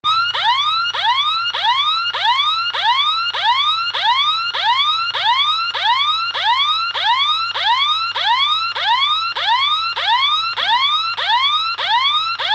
Dome Hooter : 100dB sound with adjustable volume knob and flasher in wall mounting type
2Volume100 dB max. adjustable with knob on top side
ESD-Standard-Tone-Hooter.mp3